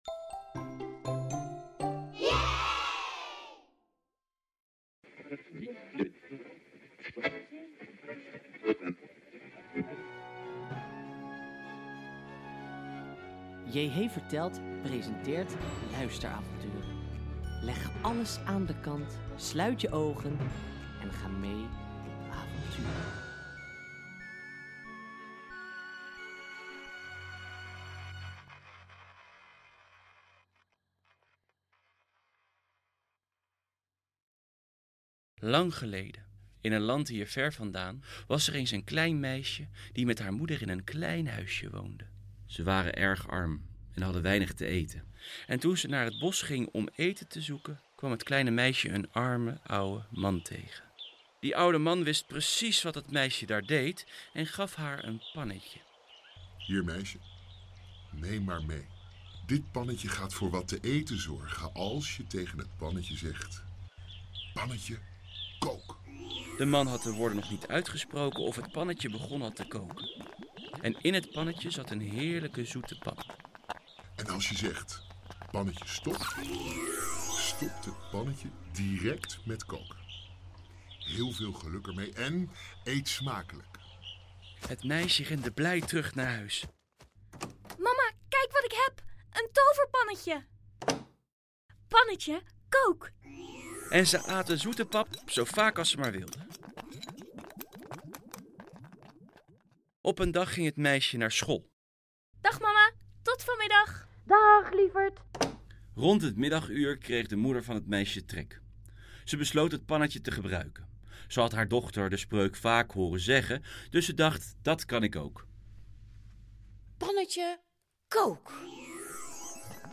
De toverpan (Een luisteravontuurtje) 3:23